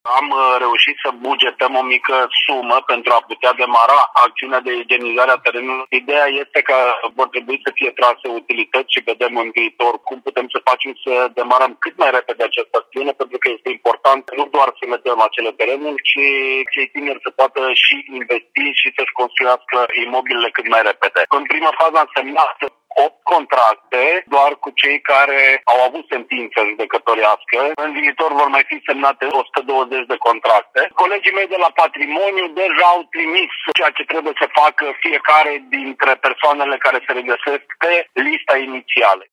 În total sunt 128 de parcele în zona Ovidiu Balea ce trebuie acordate beneficiarilor. Acestea însă nu au încă o rețea pentru conectarea la serviciile de utilități, spune viceprimarul Cosmin Tabără.